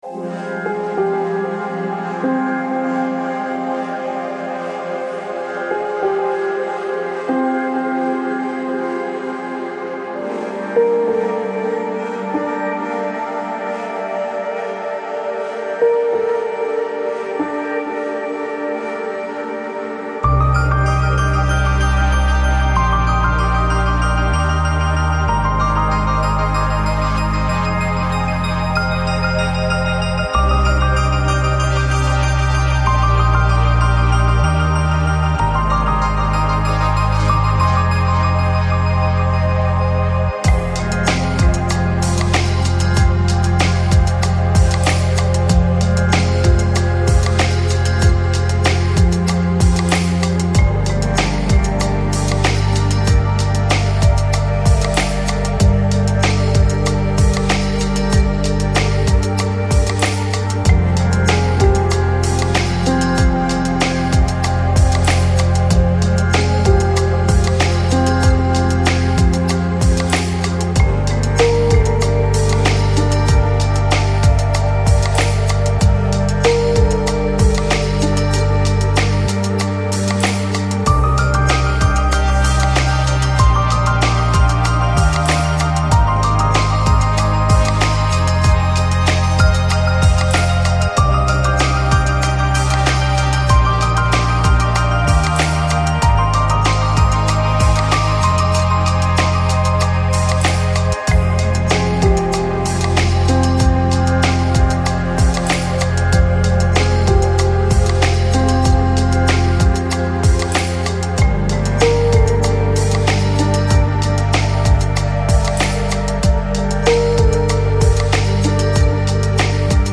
Жанр: Chill Out, Lounge, Downtempo, Ambient